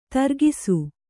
♪ targisu